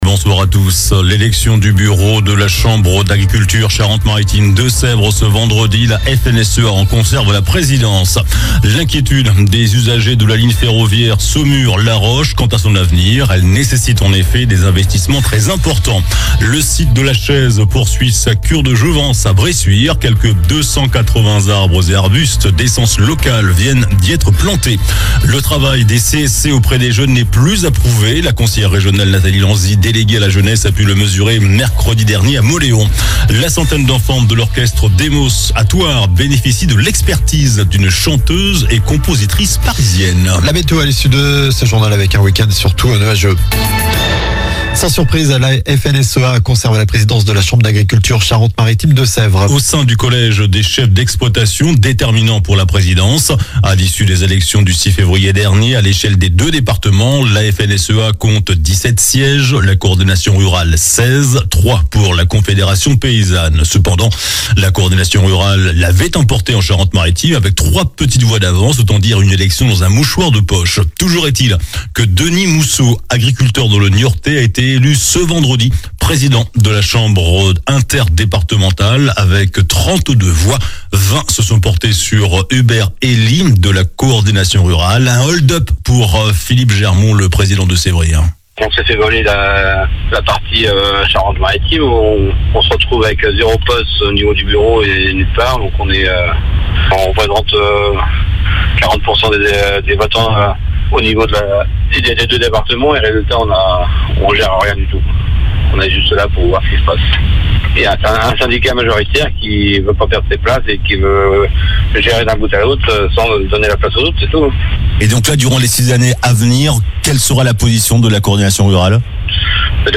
JOURNAL DU VENDREDI 21 FEVRIER ( SOIR )